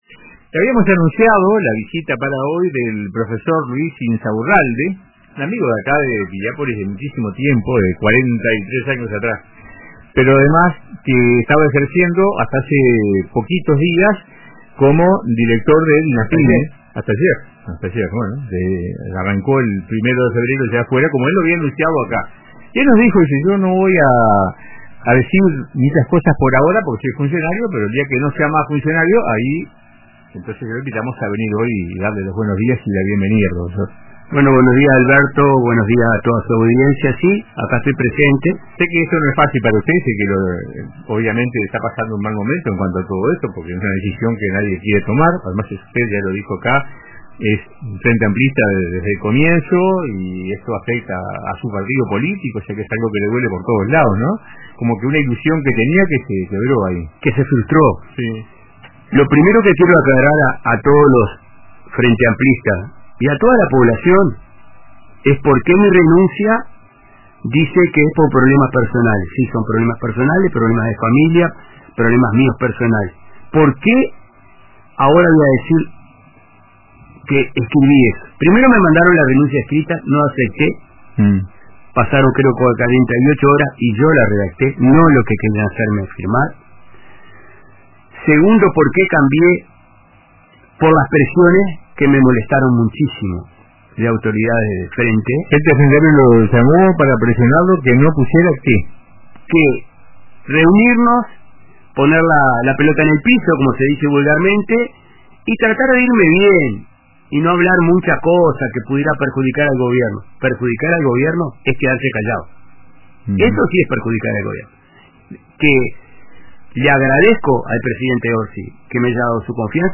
El director nacional de Artesanía, Pequeñas y Medianas Empresas (Dinapyme), Luis Inzaurralde, presentó su renuncia al cargo. El exjerarca participó del programa “Radio con Todos” de RBC, donde explicó que su decisión estuvo motivada por la falta de apoyo de la ministra Cardona a su gestión.